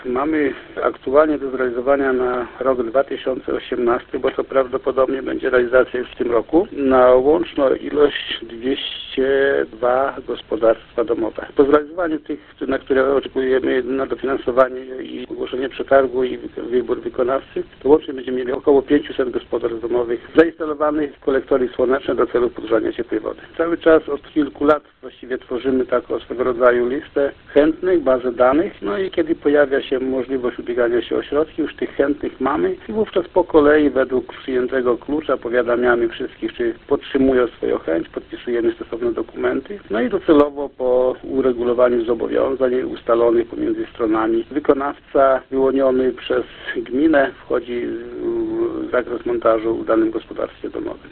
Jak mówi wójt Stanisław Szleter – będzie to już czwarty projekt polegający na montażu instalacji solarnych do podgrzewania ciepłej wody realizowany przez gminę.